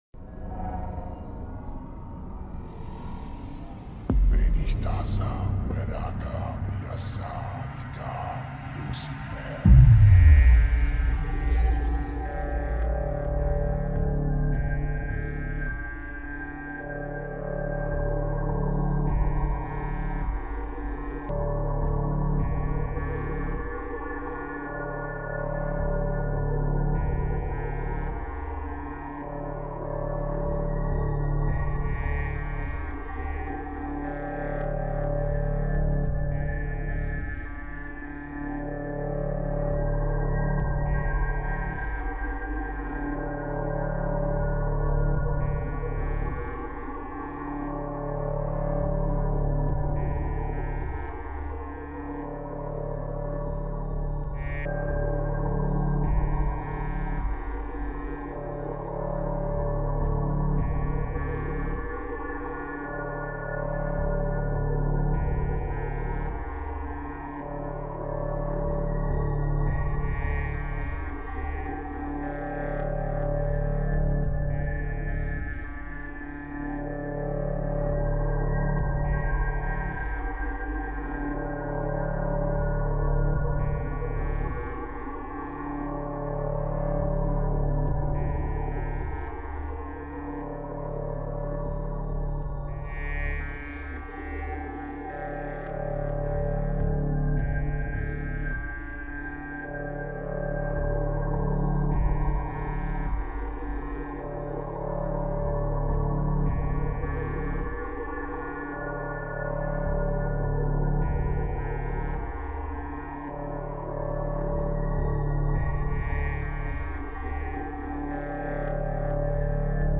Binaural | Onda Teta Profunda ~ Frequência de Sigilo 432 Hz
Binaural Meditation Chant | Deep Theta Wave ~ Sigil Frequency 432 Hz
Binaural Theta Wave Frequency 432 Hz (Third Eye Activation/Mantra) - 1 Hour Demonic Mantra